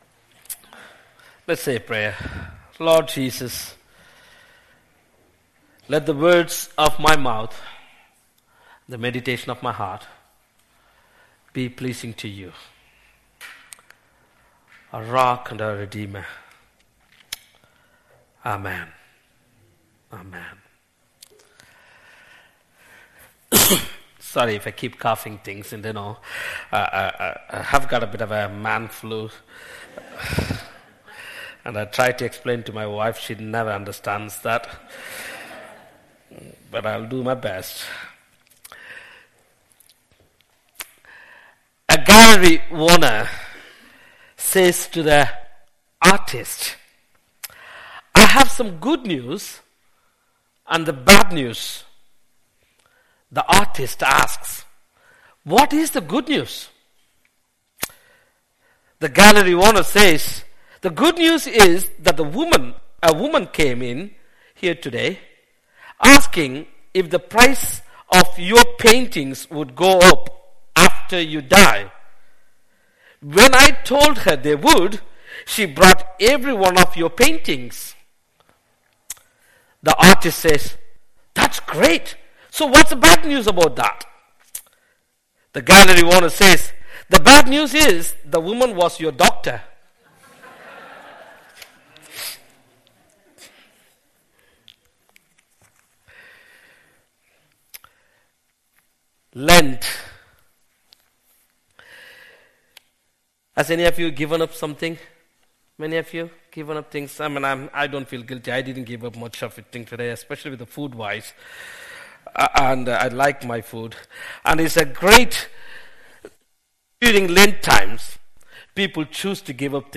An audio version of the sermon is also available.
Passage: Luke 4:1-13 Service Type: Sunday Morning This morning’s service was led by our Minister
03-09-sermon.mp3